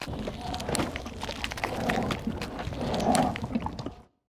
PixelPerfectionCE/assets/minecraft/sounds/mob/guardian/guardian_death.ogg at mc116
guardian_death.ogg